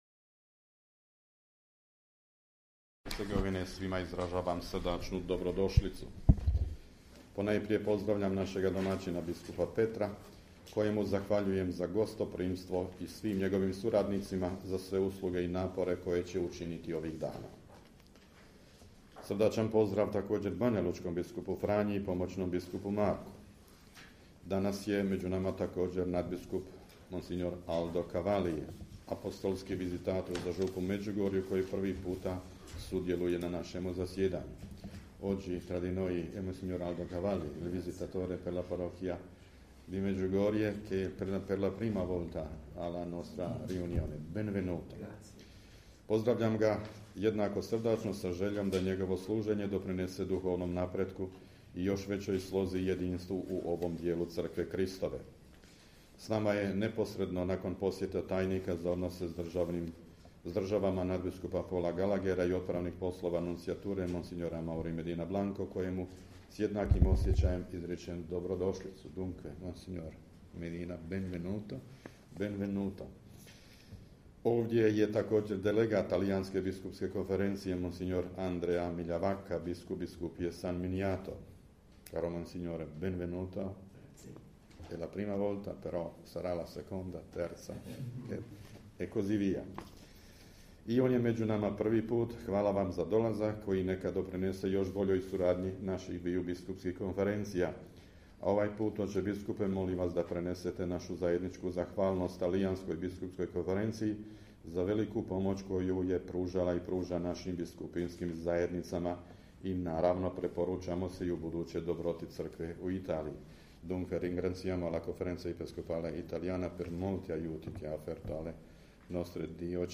Biskupska konferencija Bosne i Hercegovine započela je svoje 83. redovito zasjedanje u prostorijama Biskupskog ordinarijata u Mostaru, 21. ožujka 2022. pod predsjedanjem nadbiskupa metropolita vrhbosanskog i apostolskog upravitelja Vojnog ordinarijata u BiH mons. Tome Vukšića, dopredsjednika BK BiH. Na početku zasjedanja sve je pozdravio nadbiskup Vukšić, a njegov pozdrav prenosimo u cijelosti.